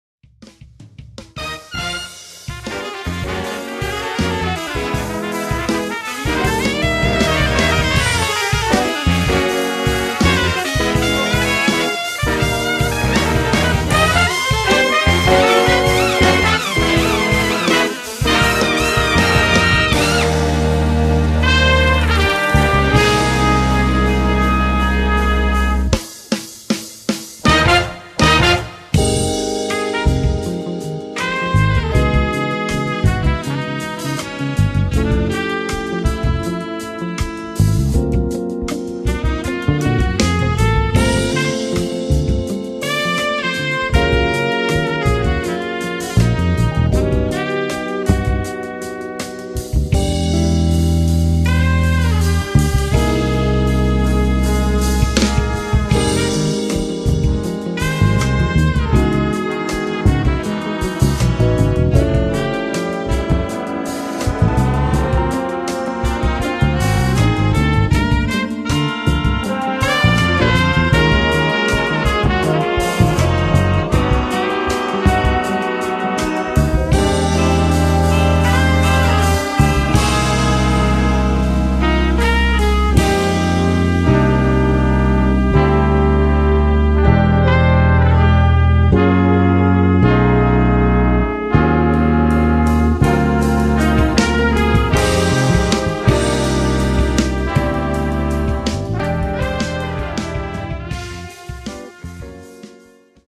Instrumentation: Big Band (4/5 Trumpets, 4 Trombones)